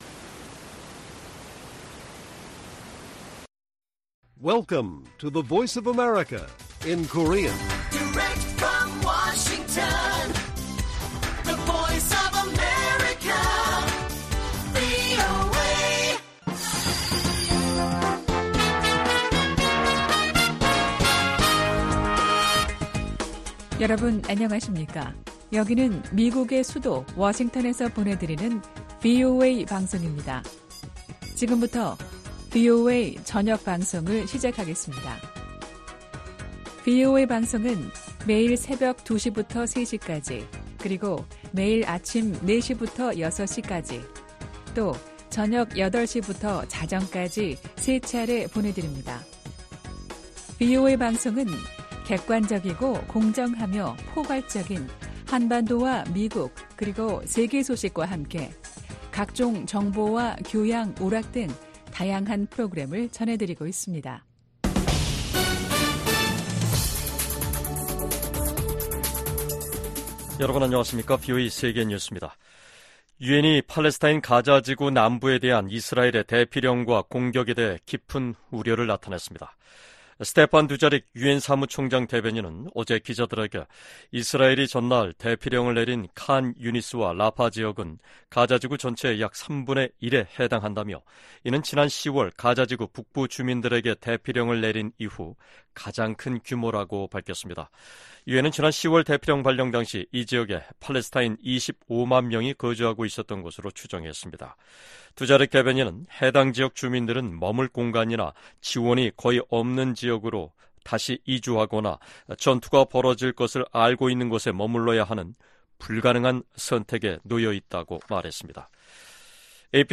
VOA 한국어 간판 뉴스 프로그램 '뉴스 투데이', 2024년 7월 3일 1부 방송입니다. 미국 국방부가 북한의 최근 탄도미사일 발사를 비판하며 계속 심각하게 받아들일 것이라고 밝혔습니다. 미국의 미사일 전문가들은 북한이 아직 초대형 탄두 미사일이나 다탄두 미사일 역량을 보유하지 못한 것으로 진단했습니다. 유엔 제재하에 있는 북한 선박이 중국 항구에 입항했습니다.